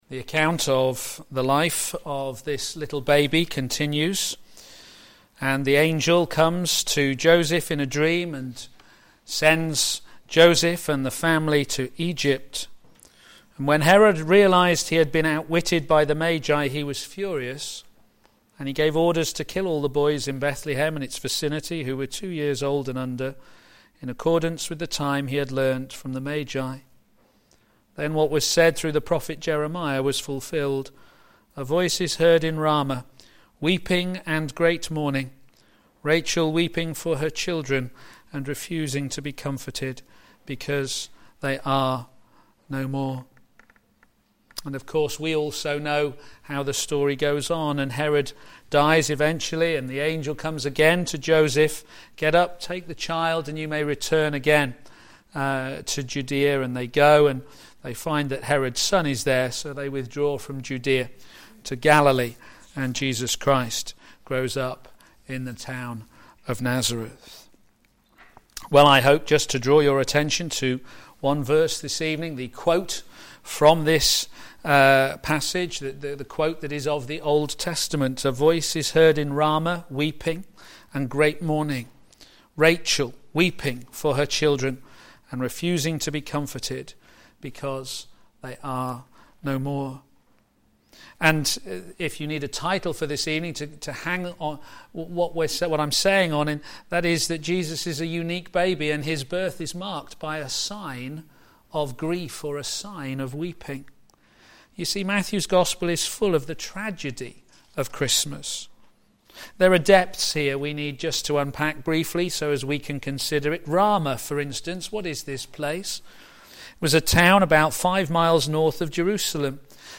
Jesus a Unique Baby Sermon